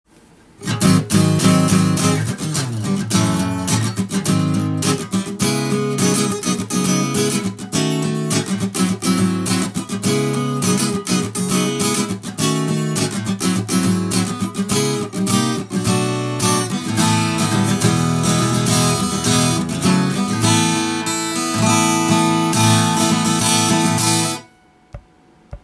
試しにこのギターでコードストロークしてみました
弾いてみるとビックリな高音でジャキジャキ言っています。
低音も程よくでるので、音も良好です。